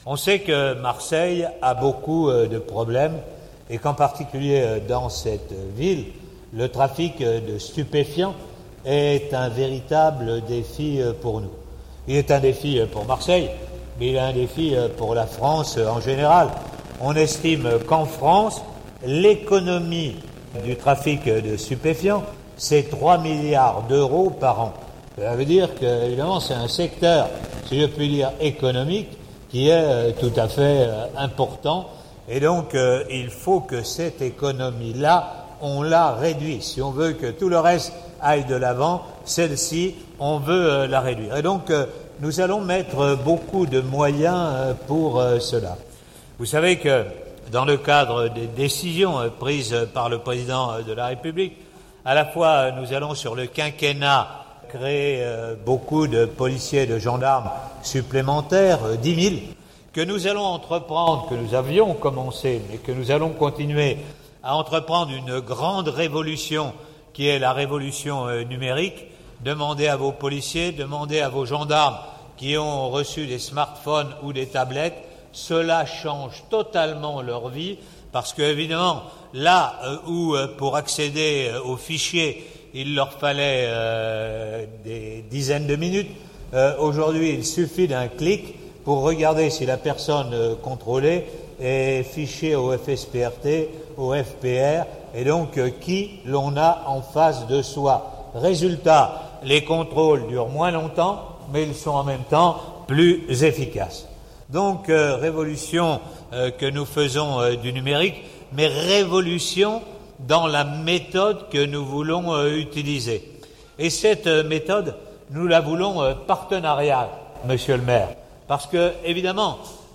jc_gaudin_discours_mairie_5_07_2018_partie.mp3